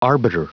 Prononciation du mot arbiter en anglais (fichier audio)
Prononciation du mot : arbiter